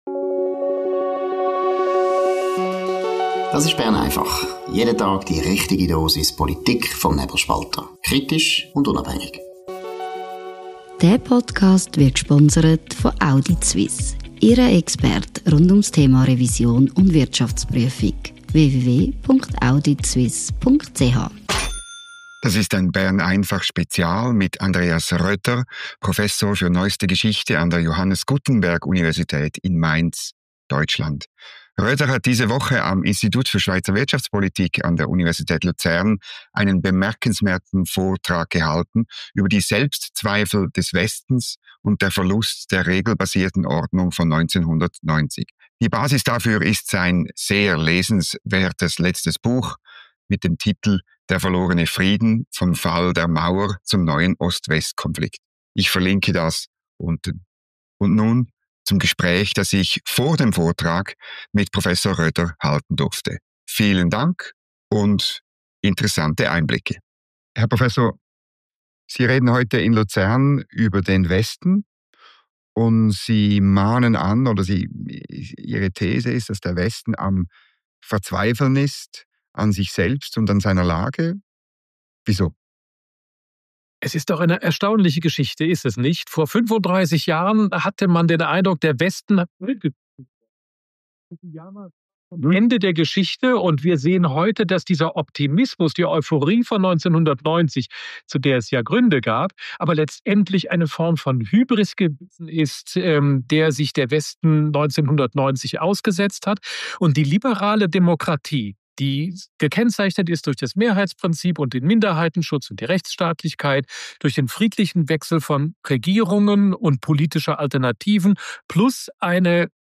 Interview mit Prof. Dr. Andreas Rödder, Universität Mainz über die Selbstzweifel des Westens und die Herausforderungen von Aussen.